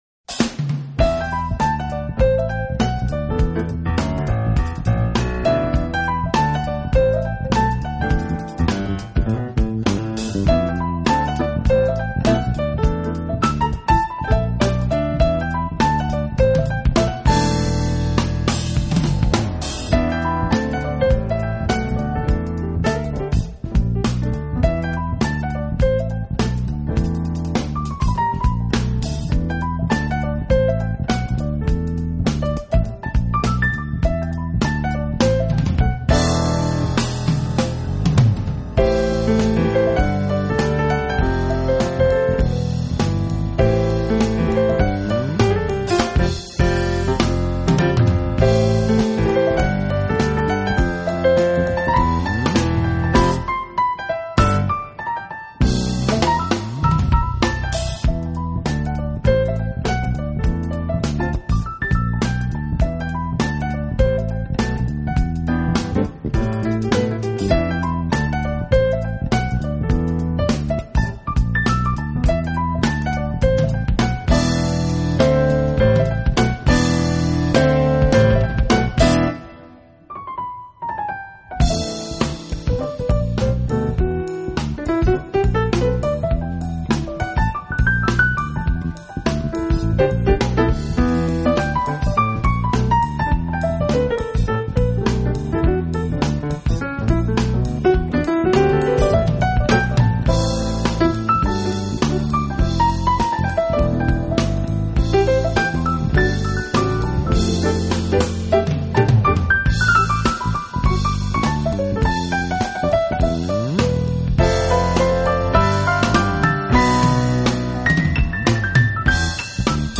the Chick Corea-ish